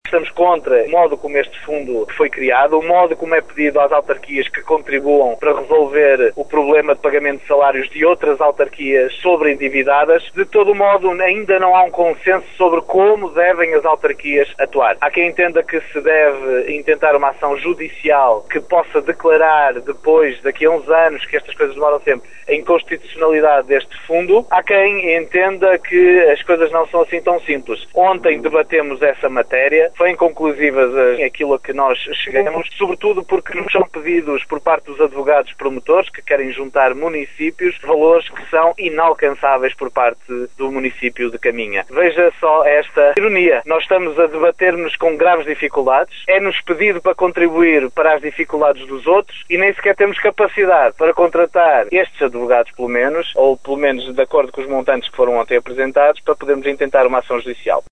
O presidente da Câmara de Caminha, Miguel Alves, foi um dos autarcas presentes e, em declarações à Rádio Caminha, revelou que não chegaram a nenhuma conclusão a não ser que é necessário contestar o fundo.